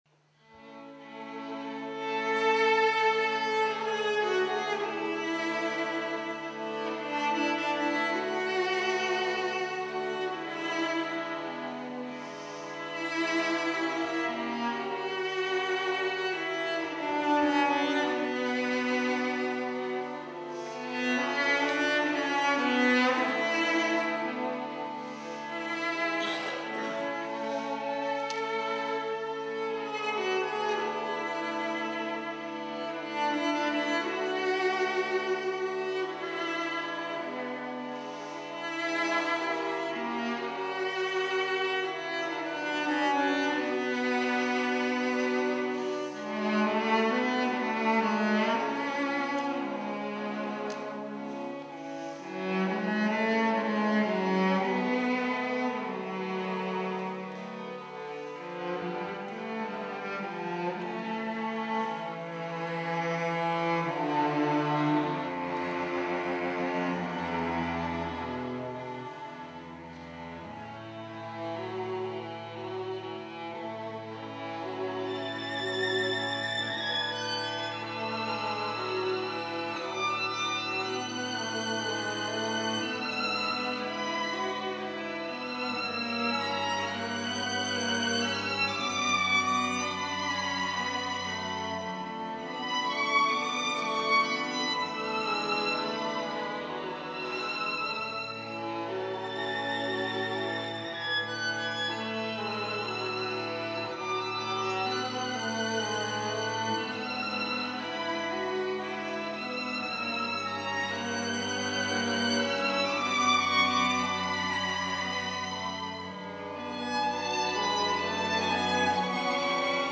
violino
viola
violoncello